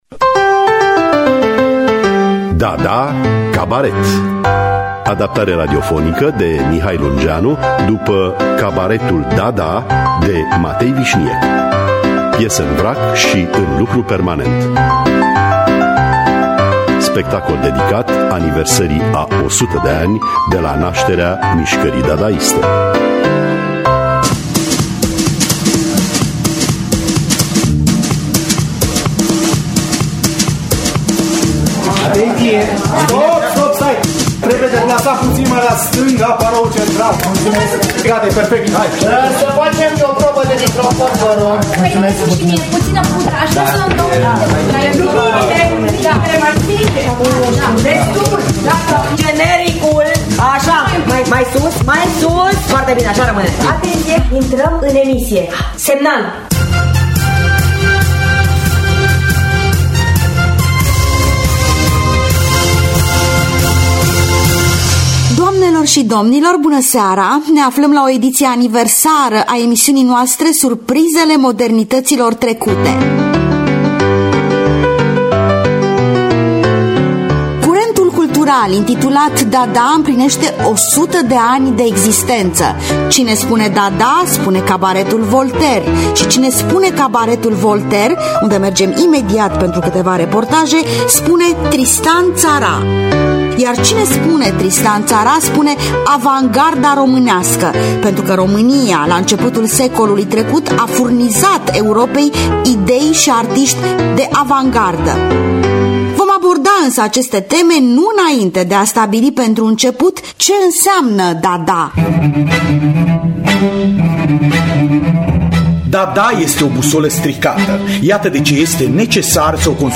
“Dada Cabaret” sau “Cabaretul Dada” de Matei Vișniec – Teatru Radiofonic Online